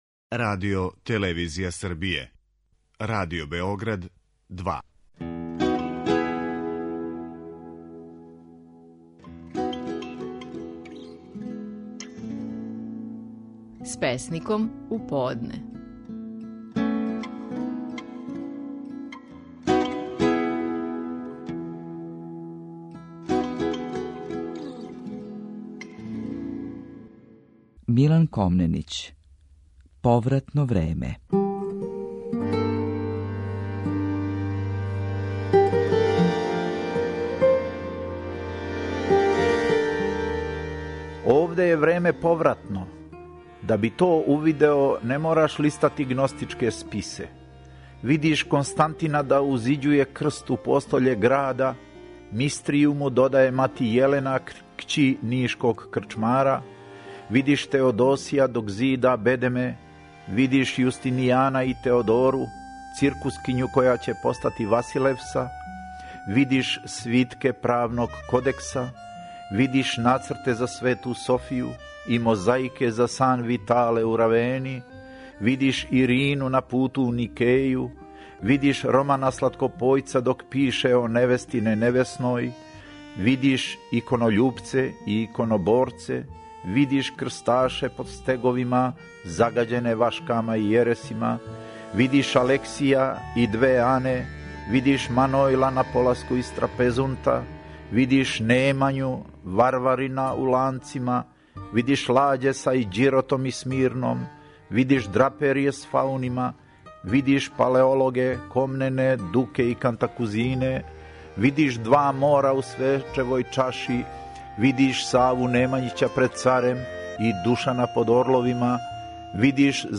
Стихови наших најпознатијих песника, у интерпретацији аутора.
Милан Комненић данас говори своју песму „Повратно време".